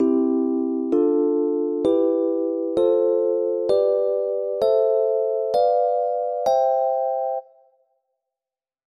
C Major – D Minor – E Minor – F Major – G Major – A Minor – B Diminished – C Major
C-Scale-Chords.wav